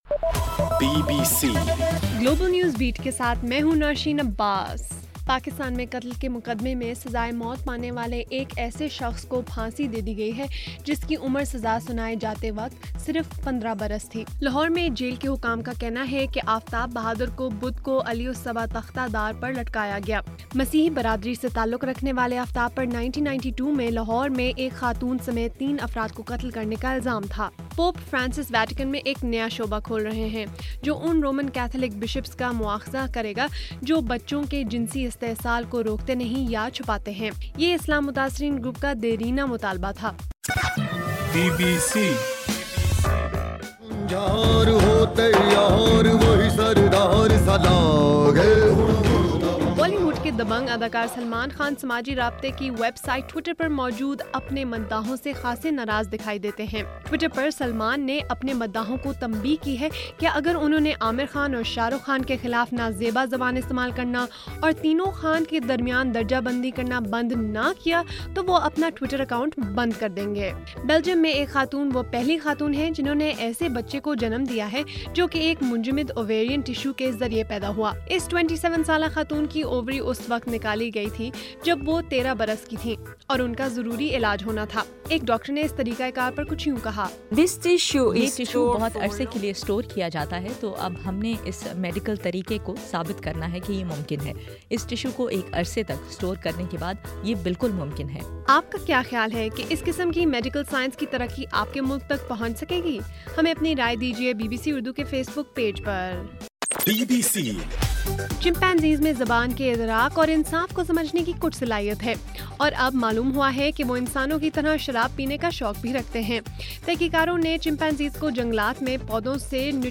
جون 10: رات 12 بجے کا گلوبل نیوز بیٹ بُلیٹن